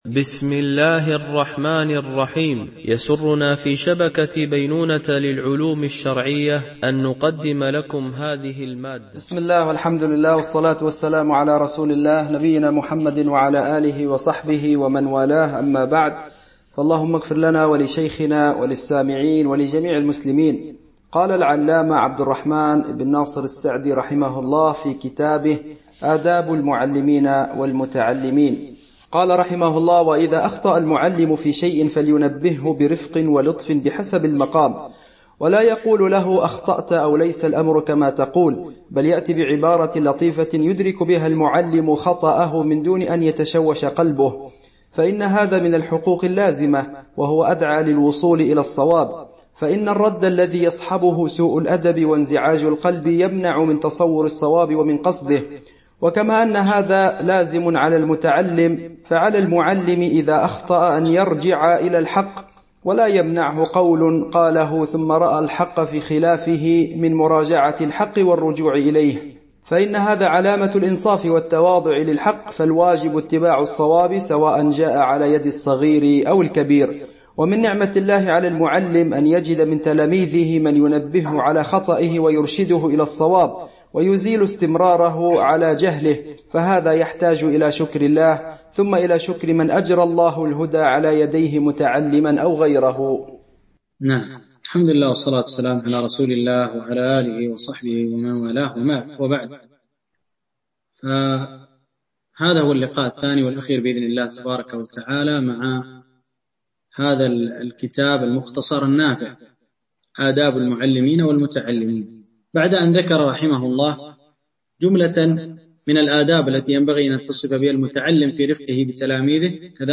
دورة علمية عن بعد
التنسيق: MP3 Mono 22kHz 32Kbps (CBR)